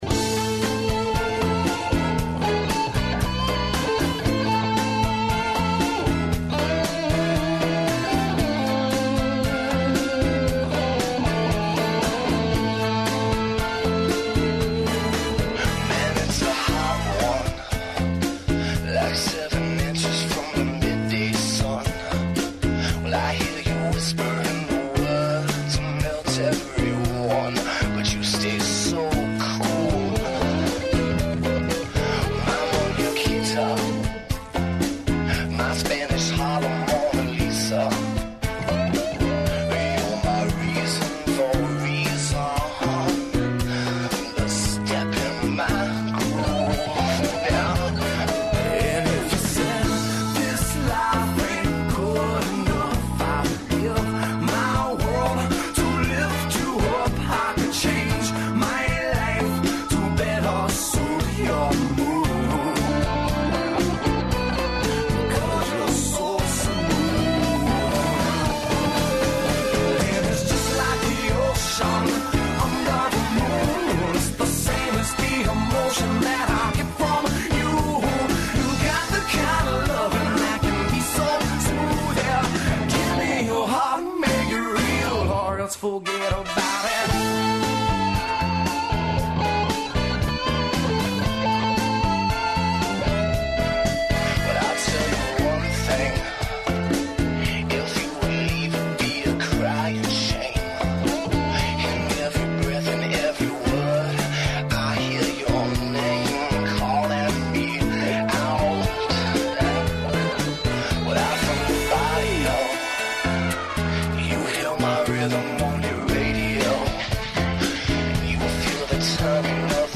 Klincijada 202 uživo iz Vranja
U današnjem, vanrednom, izdanju emisije Klincijada 202, koju realizujemo uživo iz Vranja, predstavljamo mališane iz predškolske ustanove "Naša radost", učenike muzičke škole Stevan Mokranjac.